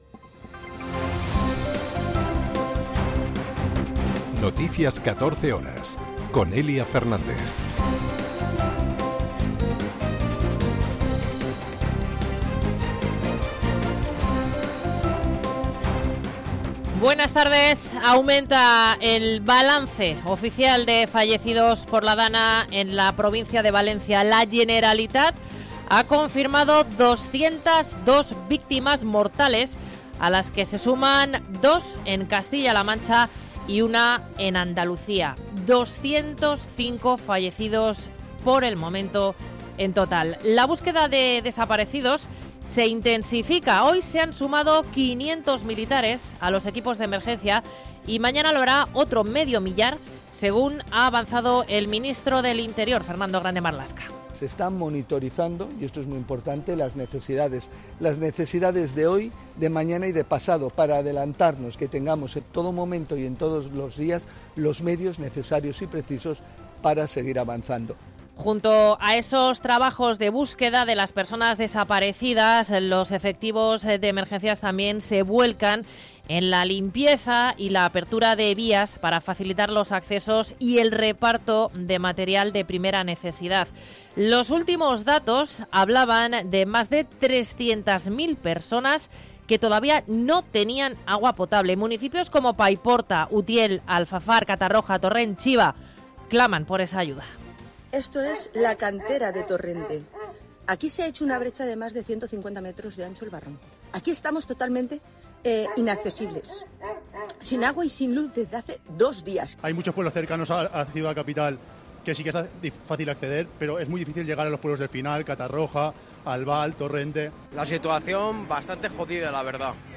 Noticias 14 horas 01.11.2024
en España y en el Mundo. 60 minutos de información diaria con los protagonistas del día, y conexiones en directo en los puntos que a esa hora son noticia